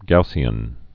(gousē-ən)